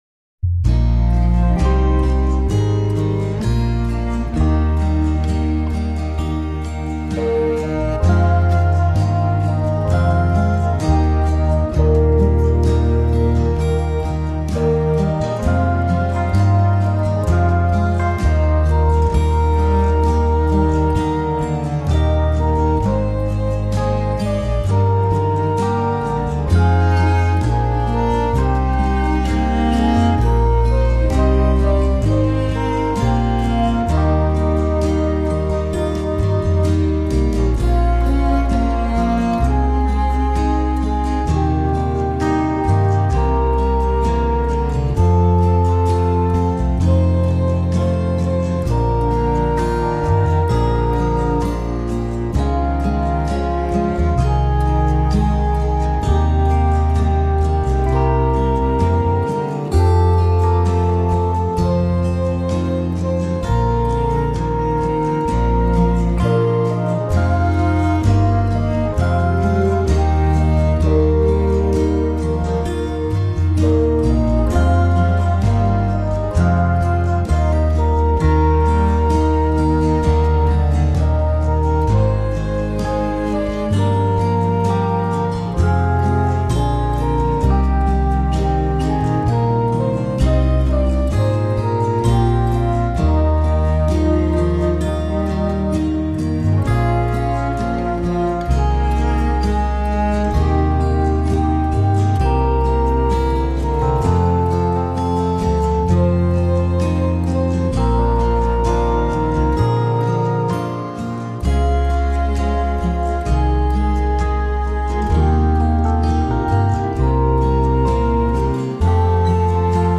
My backing is at 65 bpm.